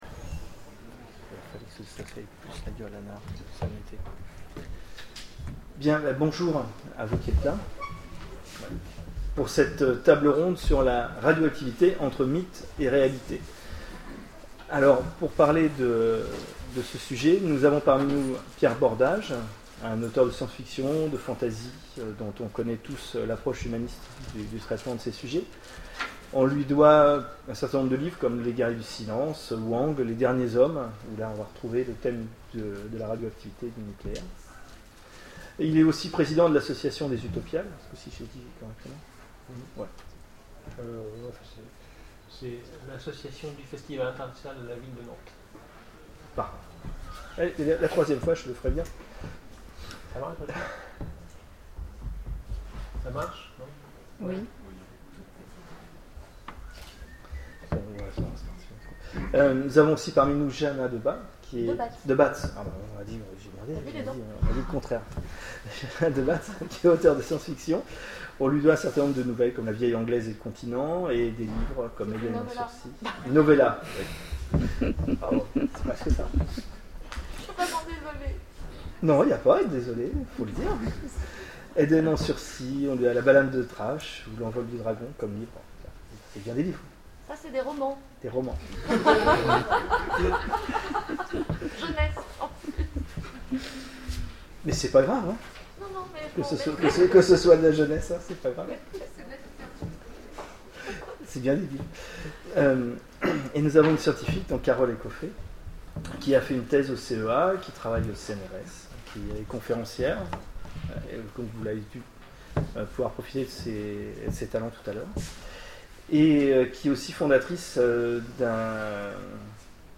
Imaginales 2011 : Conférence sur la radioactivité, entre mythe et réalité